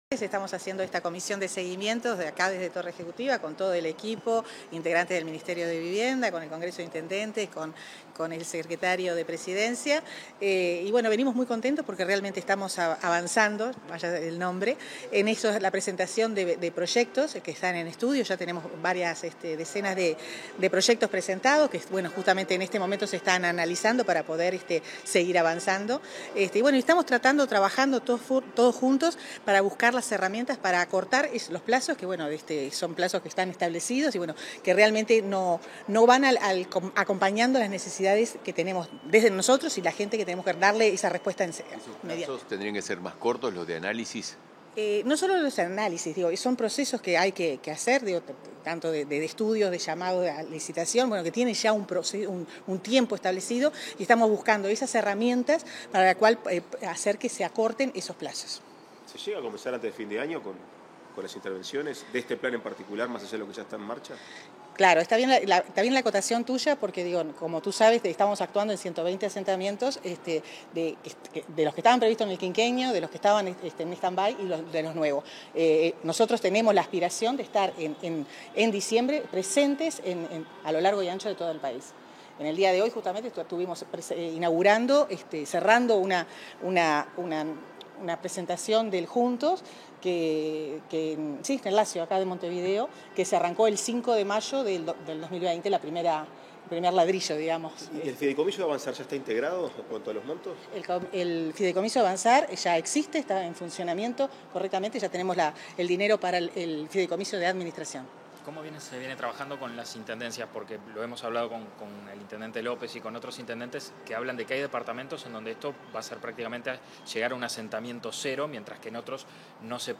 Declaraciones de la ministra de Vivienda y Ordenamiento Territorial, Irene Moreira
Declaraciones de la ministra de Vivienda y Ordenamiento Territorial, Irene Moreira 05/11/2022 Compartir Facebook X Copiar enlace WhatsApp LinkedIn Tras la reunión de la comisión de seguimiento del plan Avanzar, la ministra de Vivienda y Ordenamiento Territorial, Irene Moreira, realizó declaraciones a la prensa.